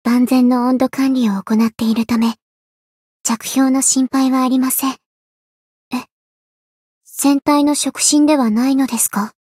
灵魂潮汐-阿卡赛特-圣诞节（摸头语音）.ogg